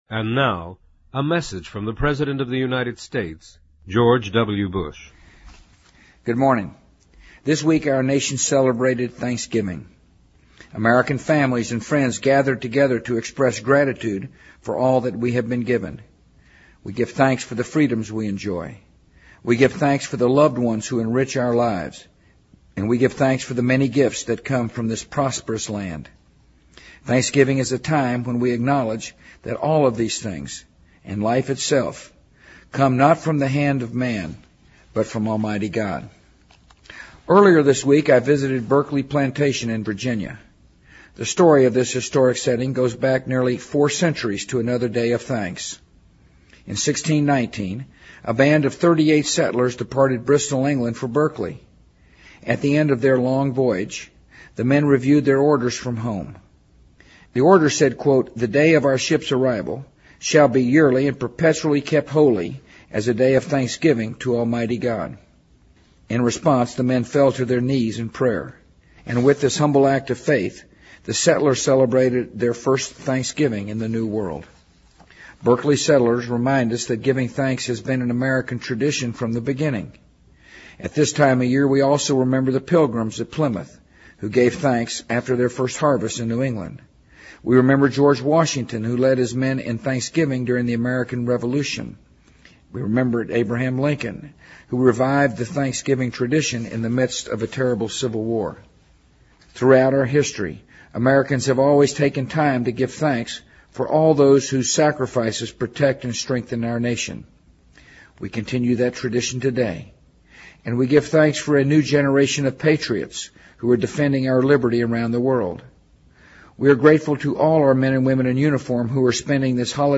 【美国总统电台演说】2007-11-24 听力文件下载—在线英语听力室